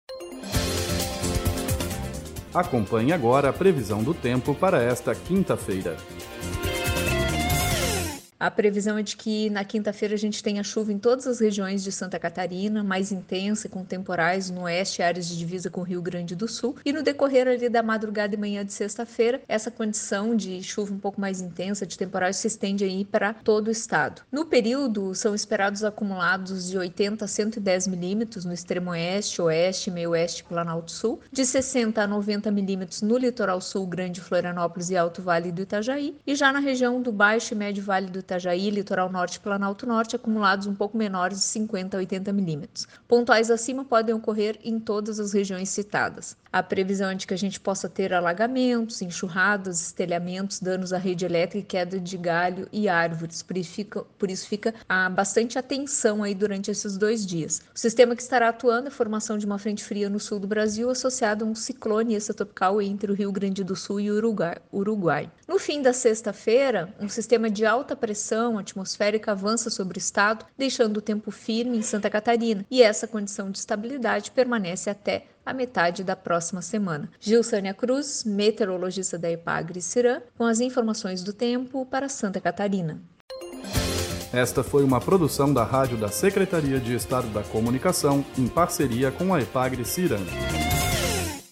Previsão do tempo para Quinta-feira, 02/11/2023 – ACN